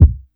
• Fat Kick Single Hit D Key 290.wav
Royality free bass drum single shot tuned to the D note. Loudest frequency: 92Hz
fat-kick-single-hit-d-key-290-qpO.wav